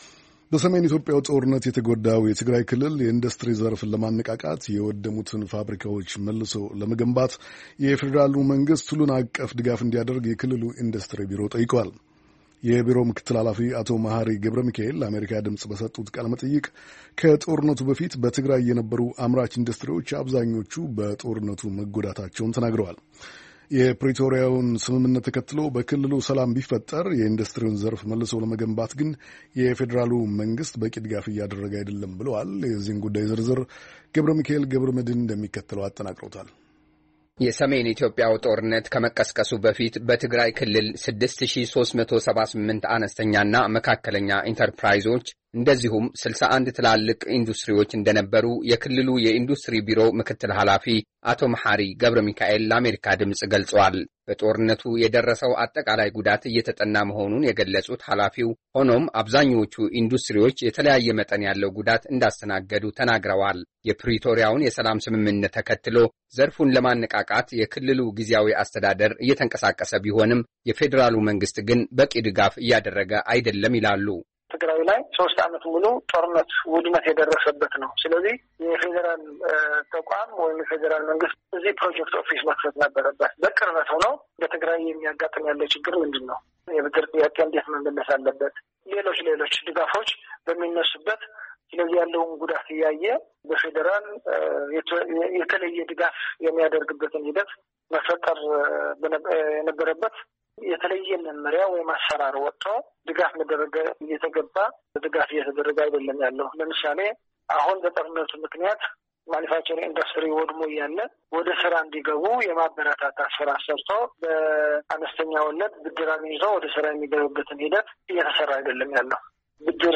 በሰሜን ኢትዮጵያ ጦርነት የተጎዳው የትግራይ ክልል የኢንዱስትሪ ዘርፍን ለማነቃቃትና የወደሙትን ፋብሪካዎች መልሶ ለመገንባት፣ የፌዴራሉ መንግሥት፣ ሁሉን አቀፍ ድጋፍ እንዲያደርግ፣ የክልሉ የኢንዱስትሪ ቢሮ ጠየቀ፡፡ የቢሮው ምክትል ኃላፊ አቶ መሐሪ ገብረ ሚካኤል ለአሜሪካ ድምፅ በሰጡት ቃለ መጠይቅ፣ ከጦርነቱ በፊት በትግራይ የነበሩ አምራች ኢንዱስትሪዎች አብዛኞቹ በጦርነቱ መጎዳታቸውን ተናግረዋል፡፡